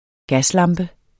Udtale [ ˈgasˌlɑmbə ]